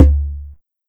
BONGO-CONGA17.wav